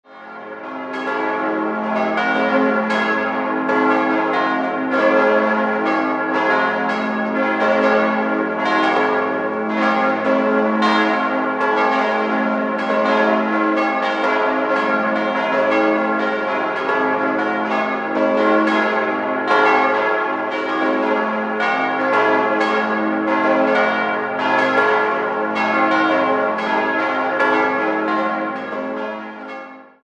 5-stimmiges Geläute: as°-c'-es'-f'-g' Die vier großen Glocken stammen aus der Gießerei Rudolf Perner, Passau, die kleine g' ist historisch. Bei der großen as° handelt es sich um eine der größten Dur-Glocken Deutschlands.